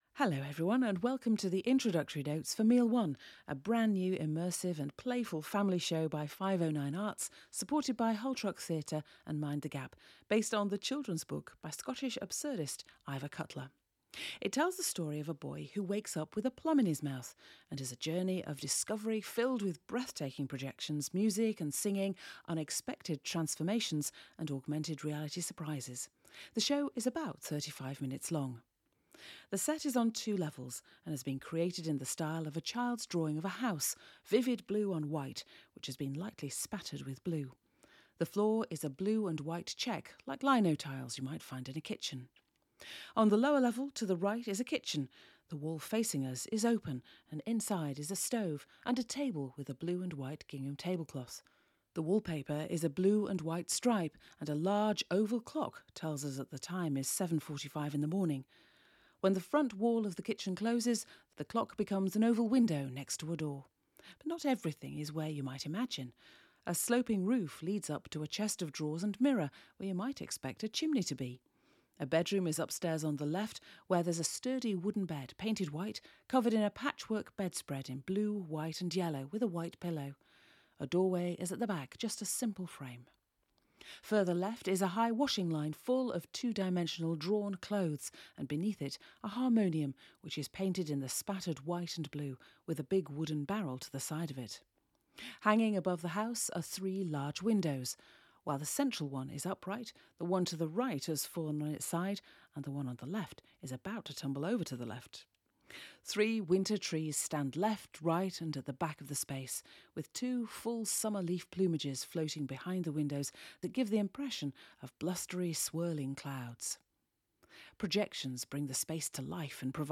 Pre-show support pack and Pre-show audio description